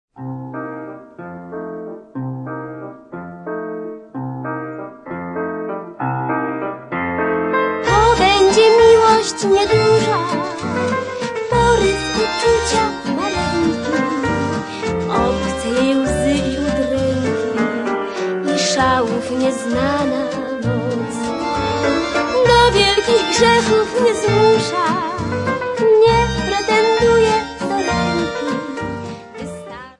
40 Hit Polish Cabaret Songs